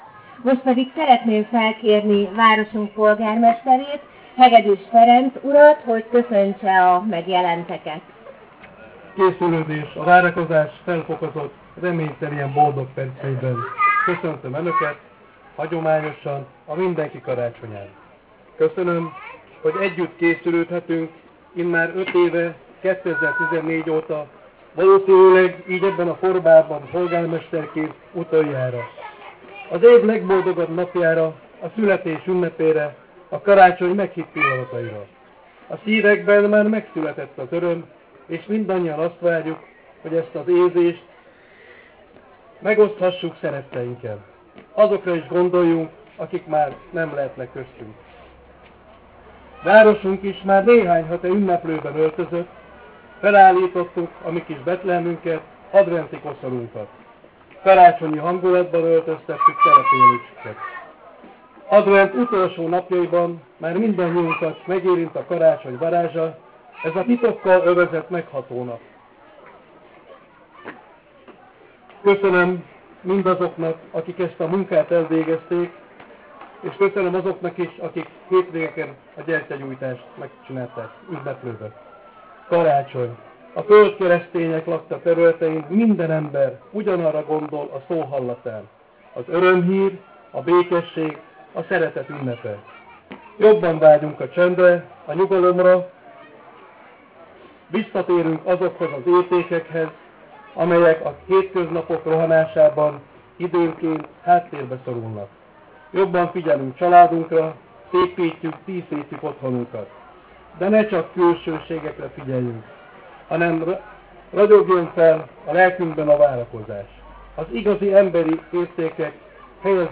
Meghallgatható az alul lévő linken) A köszöntő végén felvillantásra került az adventi koszorút a 4. gyertya, ilyenmódon teljessé vált az adventi gyertyalángok összessége.